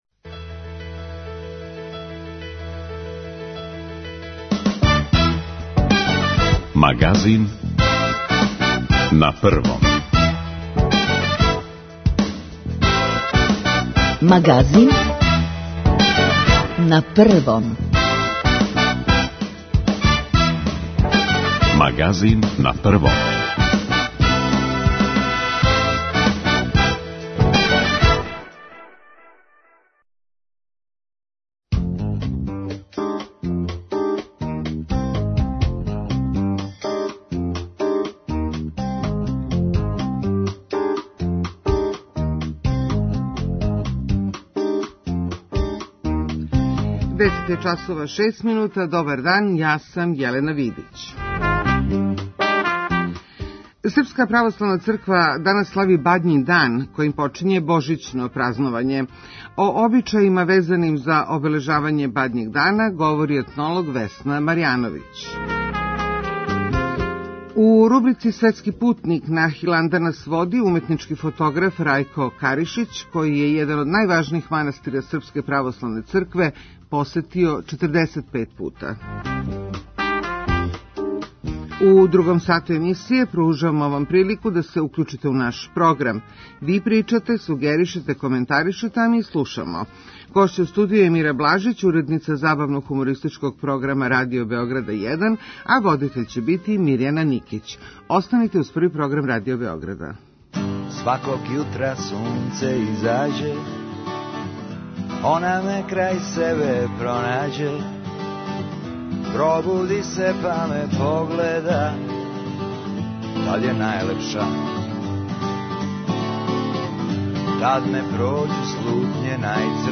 У другом сату емисије пружамо вам прилику да се укљућите у наш програм. Ви причате, сугеришете, коментаришете - ми слушамо!